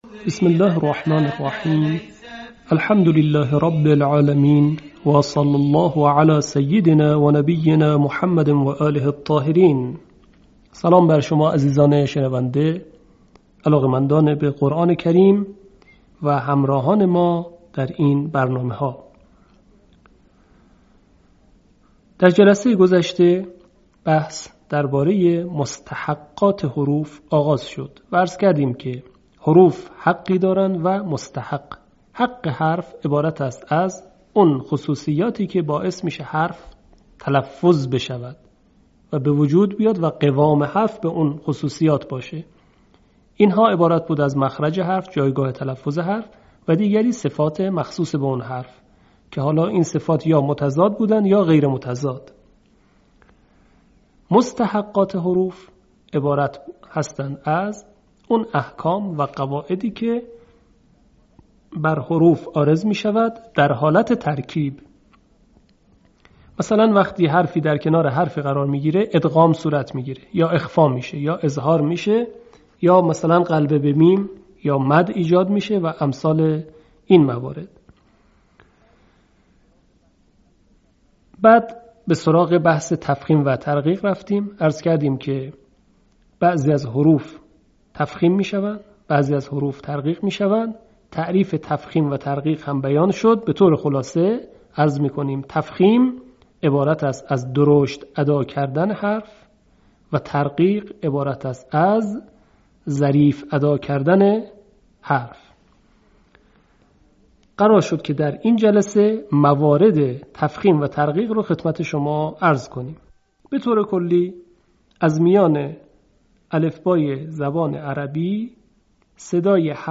به همین منظور مجموعه آموزشی شنیداری (صوتی) قرآنی را گردآوری و برای علاقه‌مندان بازنشر می‌کند.